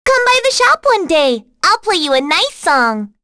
Rehartna-vox-dia_02.wav